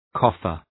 {‘kɔ:fər, ‘kɒfər}
coffer.mp3